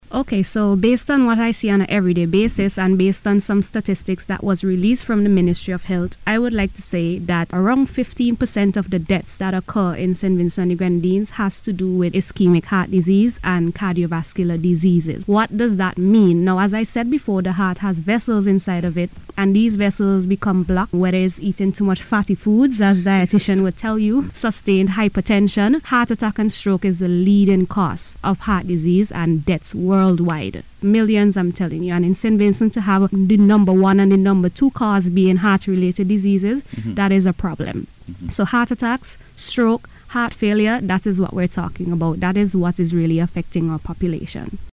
In a recent radio discussion